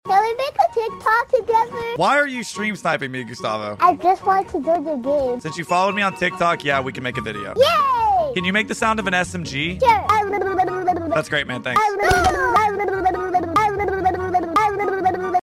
New Sounds of SMG’s in sound effects free download